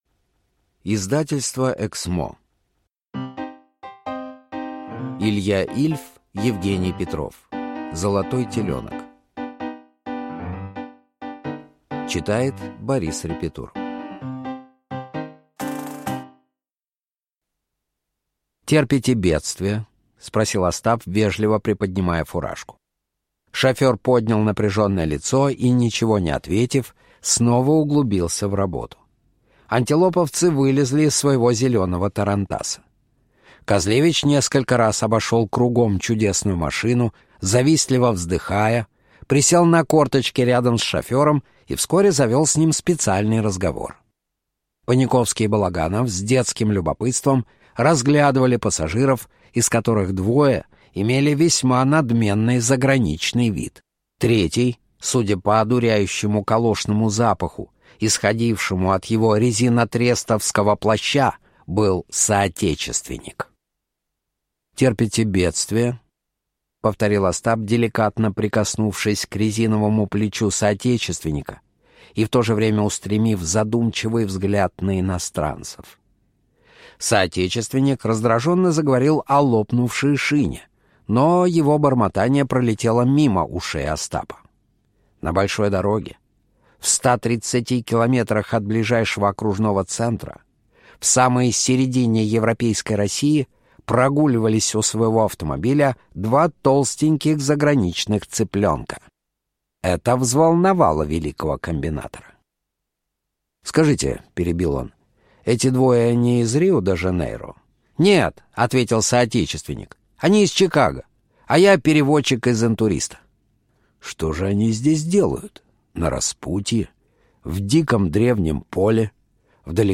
Аудиокнига Золотой теленок | Библиотека аудиокниг